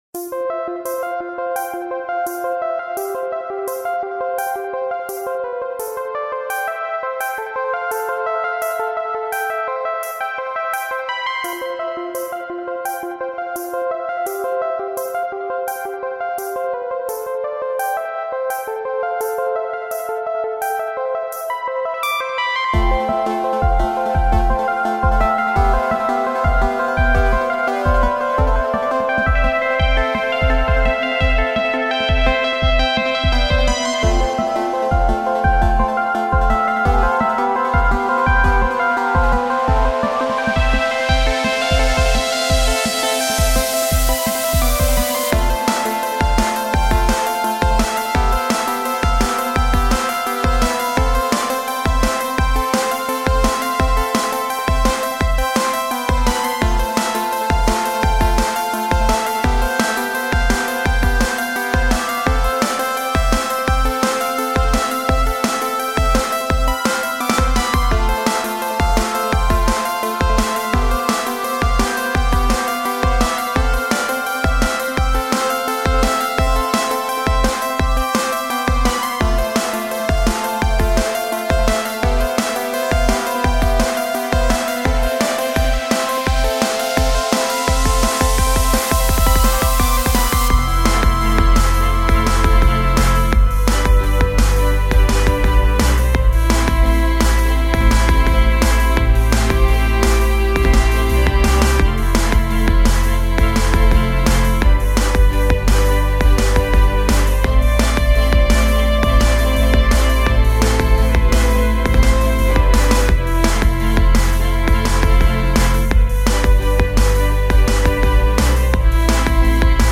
I guess it's just random music.